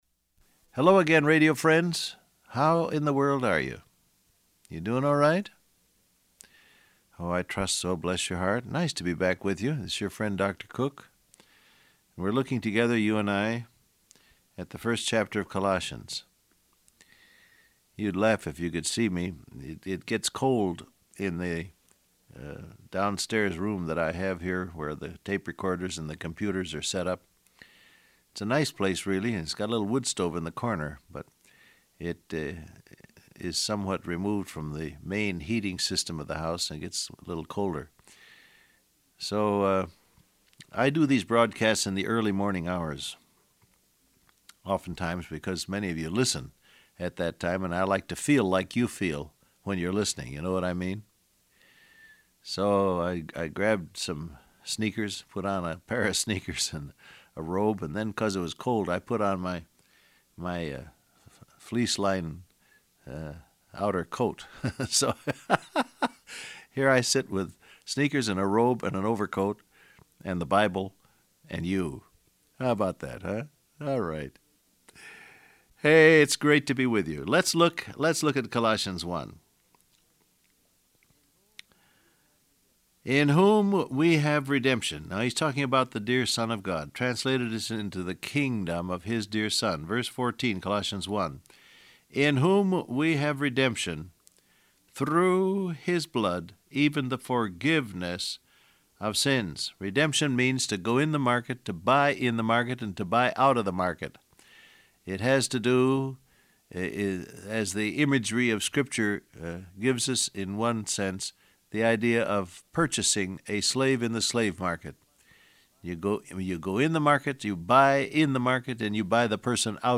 Download Audio Print Broadcast #1863 Scripture: Colossians 1:14-15 , John 14:4 Transcript Facebook Twitter WhatsApp Hello again radio friends, how in the world are you?